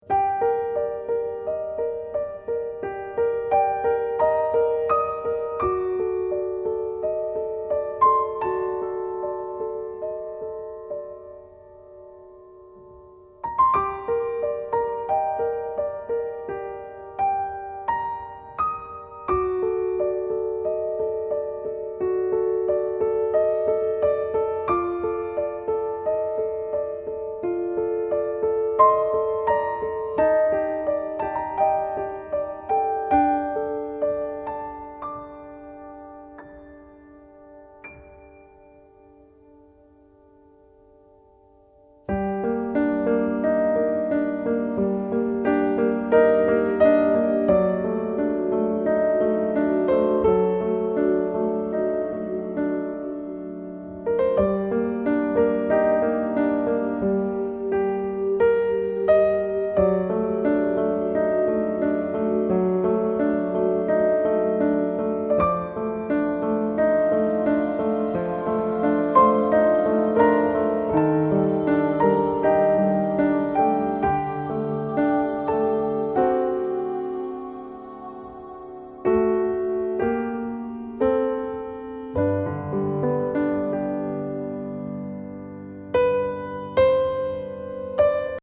Level : Easy | Key : G minor | Individual PDF : $3.99